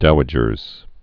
(douə-jərz)